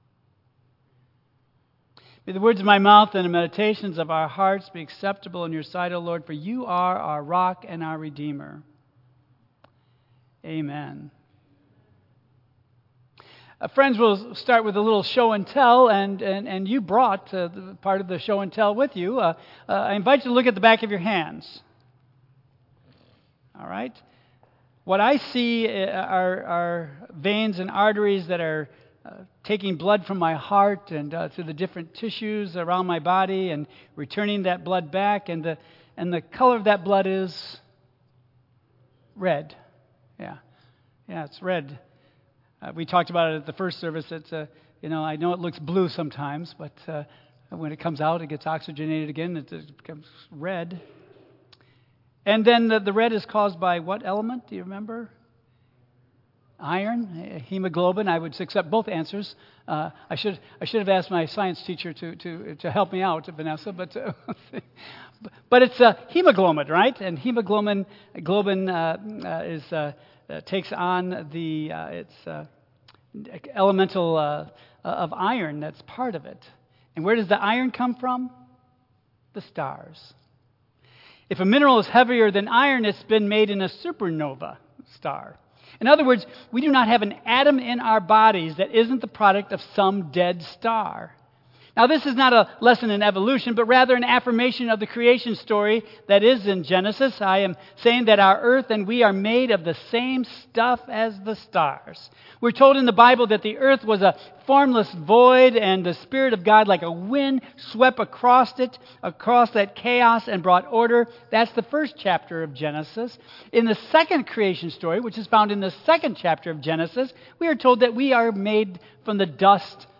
Epiphany Sunday Message The Kings followed the star to eventually come to the Christ child in Bethlehem.
Tagged with Michigan , Sermon , Waterford Central United Methodist Church , Worship Audio (MP3) 8 MB Previous A Word to the Wise: Look Forward Next The Voice of Truth